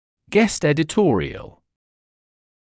[gest ˌedɪ’tɔːrɪəl][гэст ˌэди’тоːриэл]редакционная статья, написанная приглашенным автором